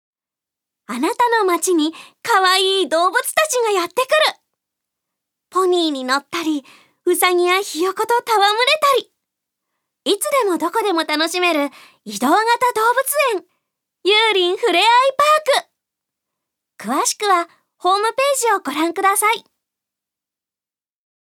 ナレーション５